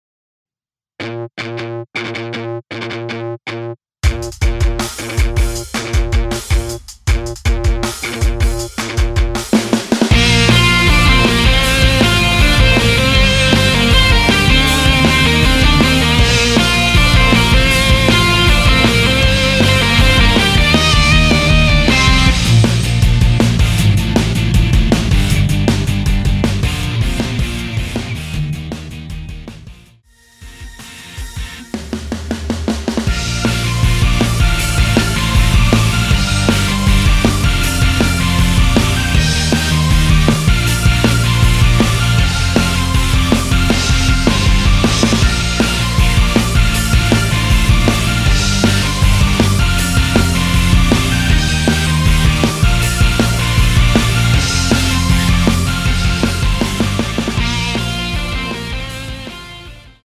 MR 입니다.
Ebm
앞부분30초, 뒷부분30초씩 편집해서 올려 드리고 있습니다.
중간에 음이 끈어지고 다시 나오는 이유는